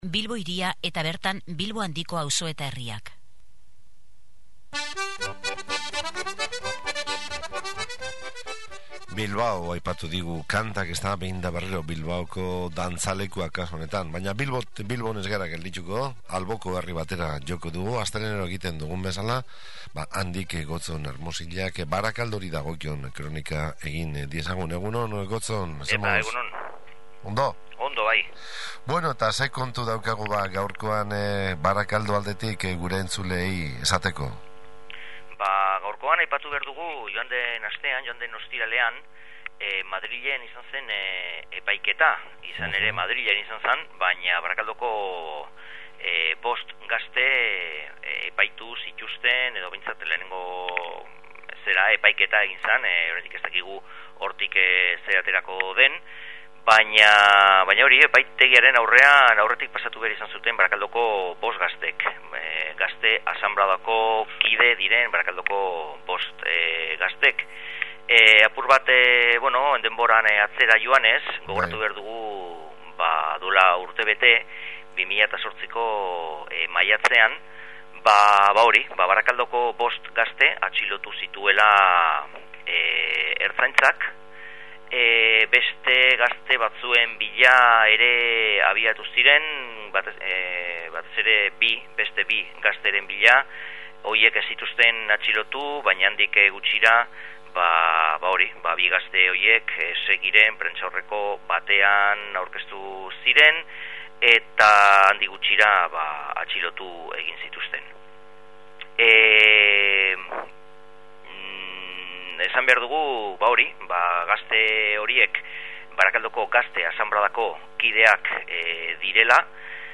SOLASALDIA: Hiritarron parte-hartzea